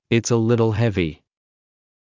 ｲｯﾂ ｱ ﾘﾄｳ ﾍｳﾞｨ